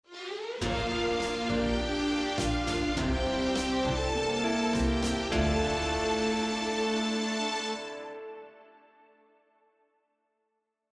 Plays end of the track